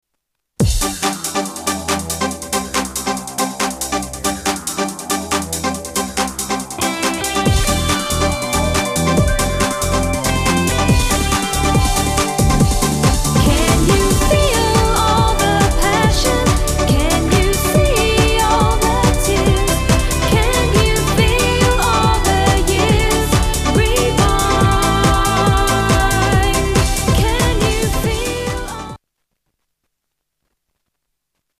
STYLE: Pop
is catchy dance pop
doing a fine job over a bouncy groove